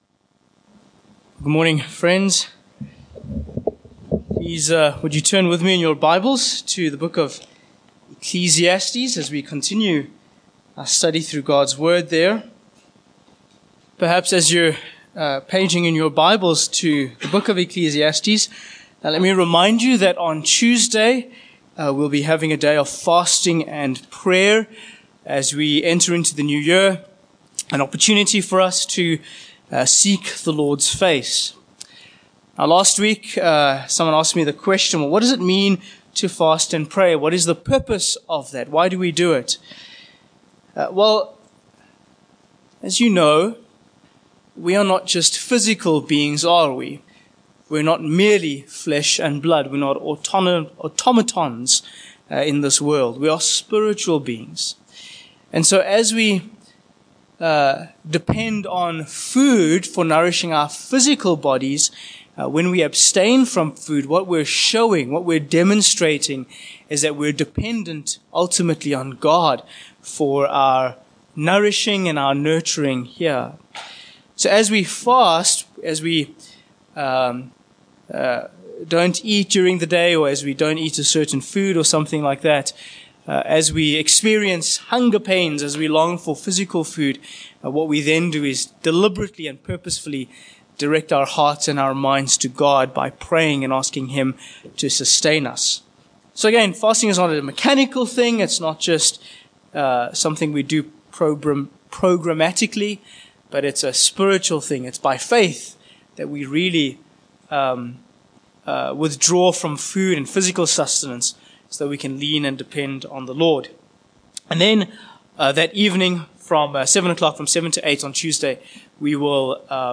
Ecclesiastes 2:1-11 Service Type: Morning Passage